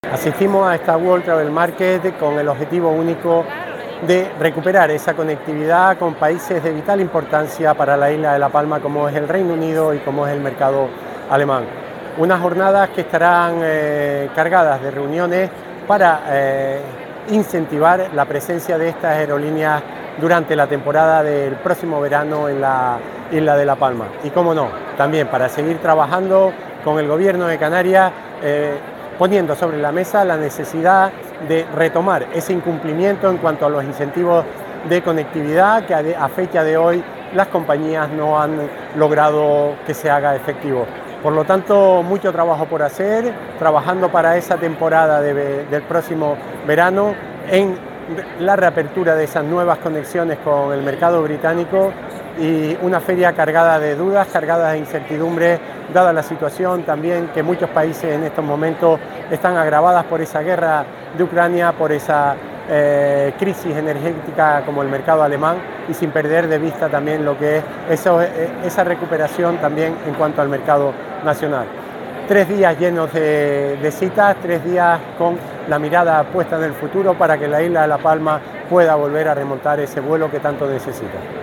Declaraciones audio Raúl Camacho WTM.mp3